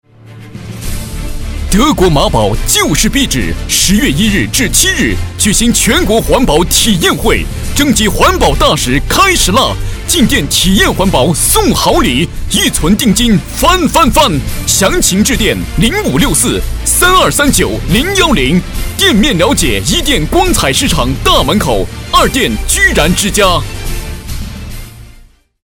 男国184年轻活力时尚配音-新声库配音网
男国184_广告_促销_壁纸广告_激情.mp3